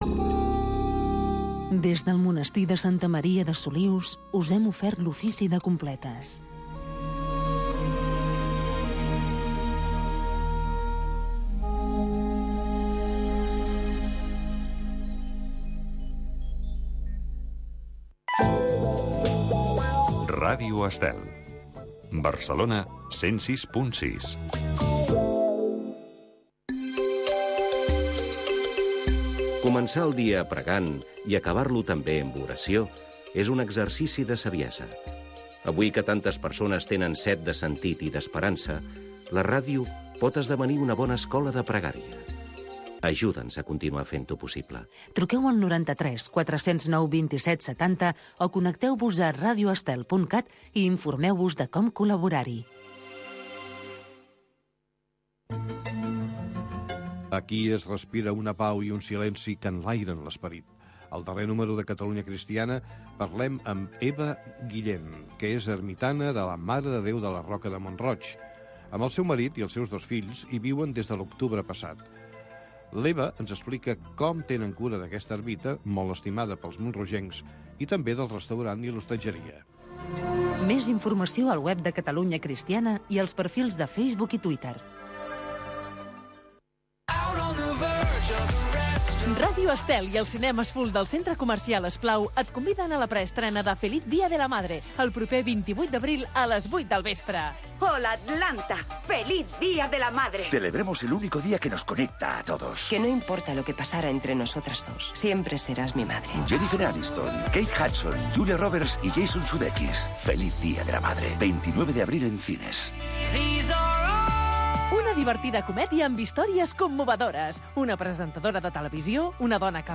Radio Vaticana. L'informatiu en castellà de Ràdio Vaticana. Tota l'activitat del pontífex, com també totes aquelles notícies de Roma.